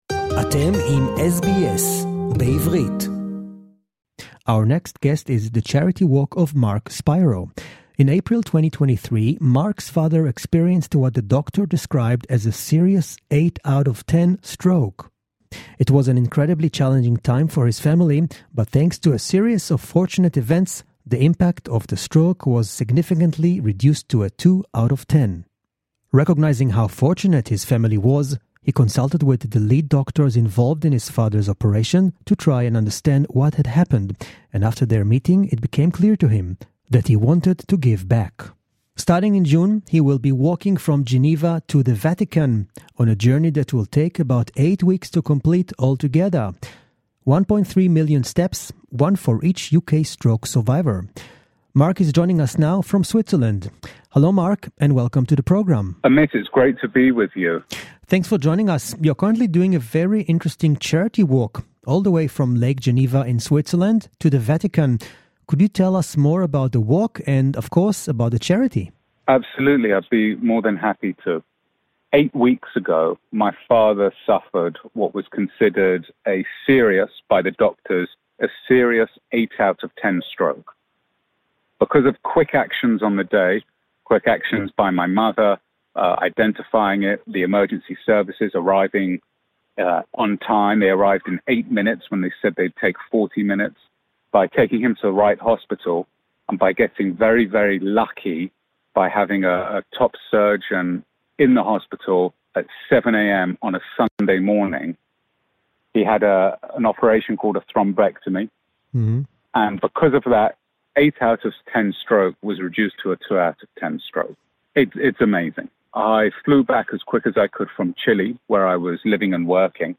Their mission is to raise funds for critical stroke-related research. In this special interview, we witness the power of determination, love, and the unbreakable bond between a father and son.